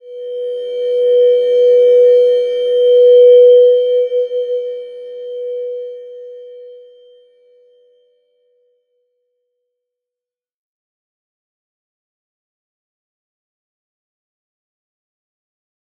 Slow-Distant-Chime-B4-f.wav